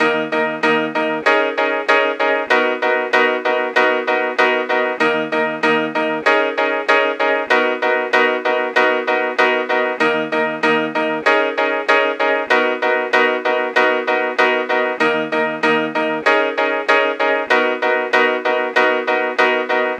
Night Rider - Steady Piano.wav